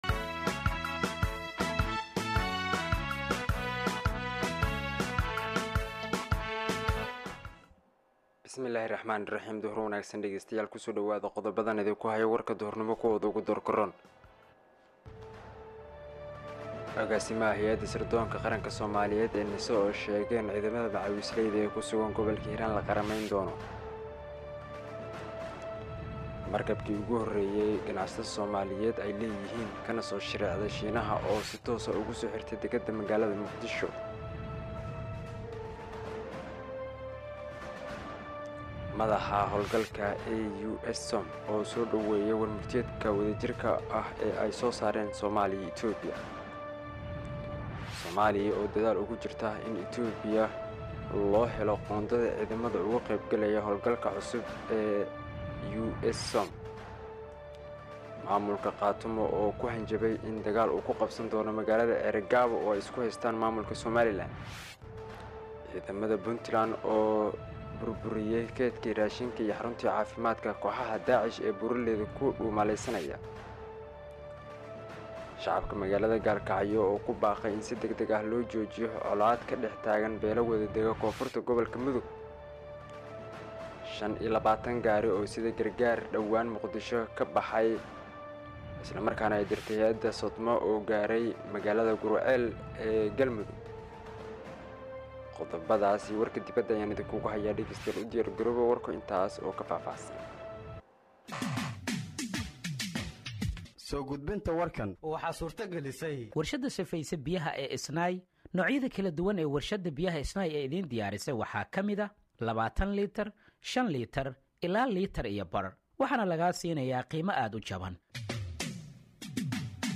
Dhageeyso Warka Duhurnimo ee Radiojowhar 13/01/2025